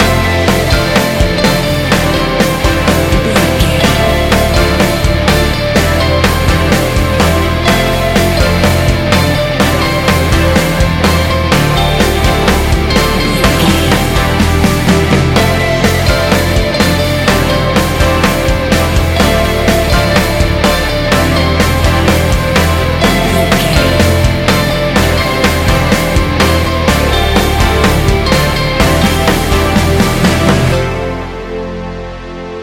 Ionian/Major
D
ambient
electronic
new age
chill out
downtempo
pads
drone